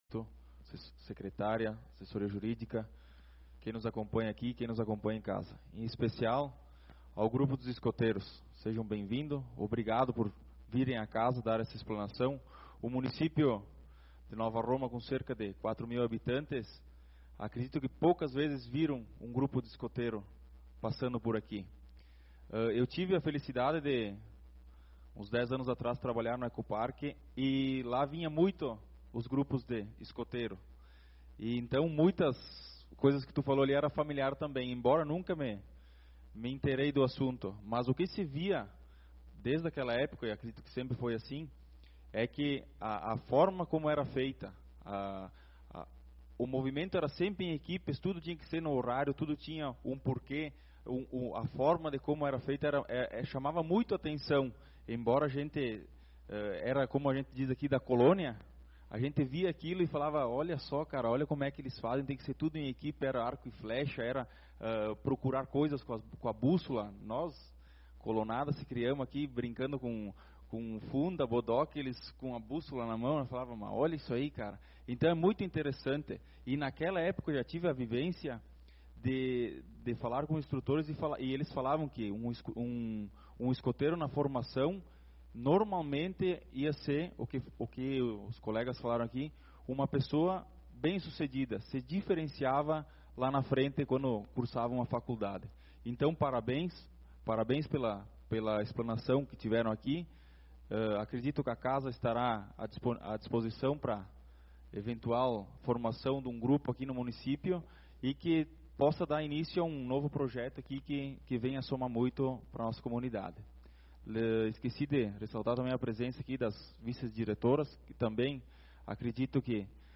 Sessão Ordinária do dia 12/11/2025
Teremos na Tribuna Livre os representantes dos Escoteiros de Antônio Prado